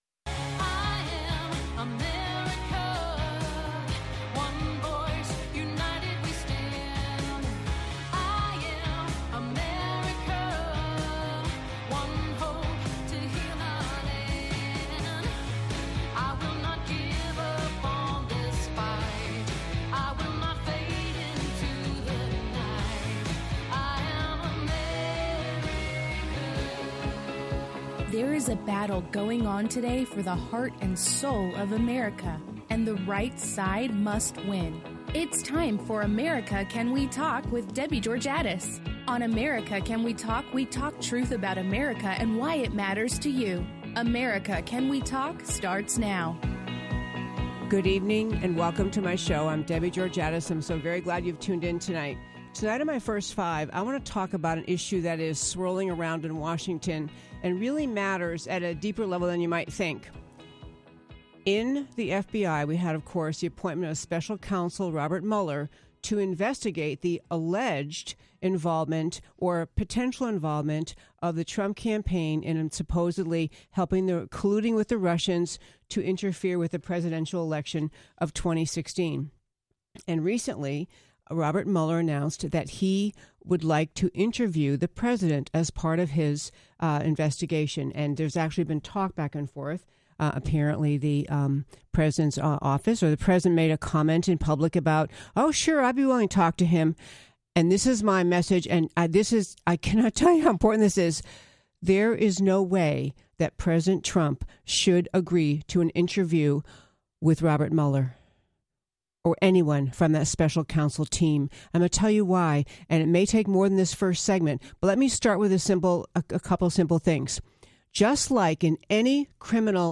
Guest interview – Candace Owens, Turning Point USA